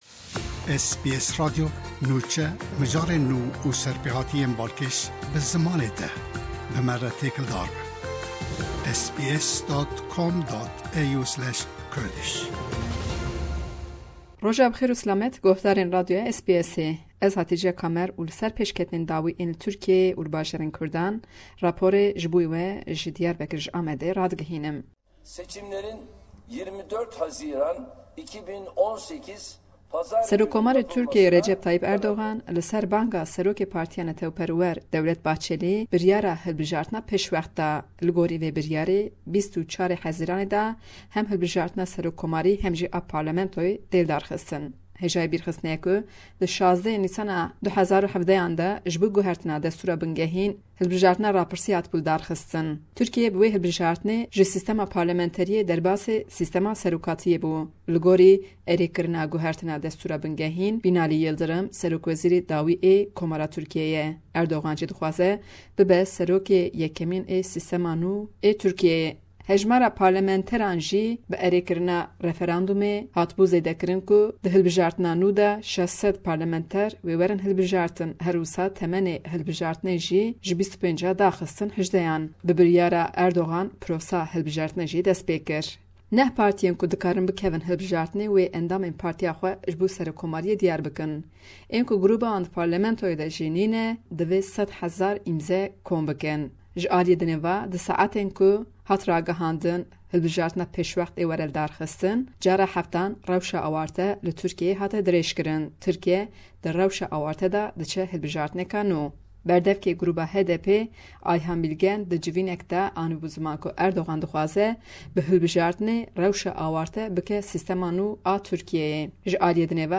Hevpeyvineke taybet